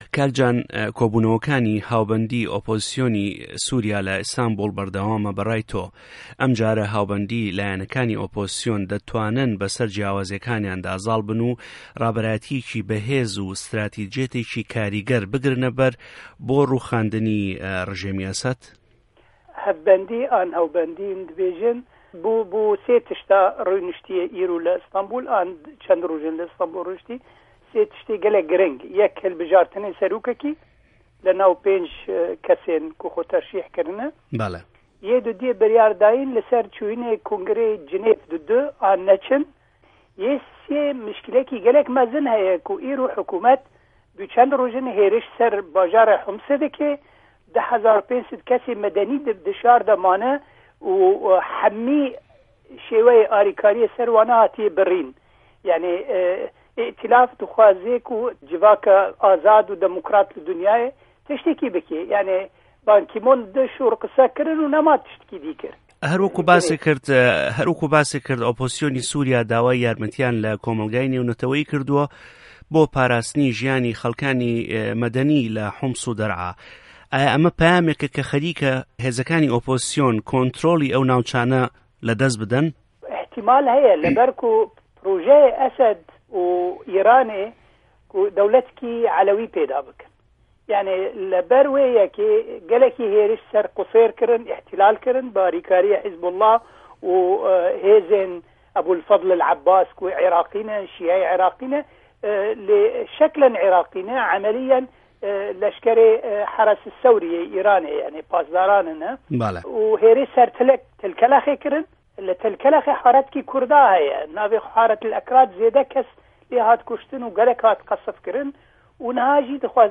سوریا - گفتوگۆکان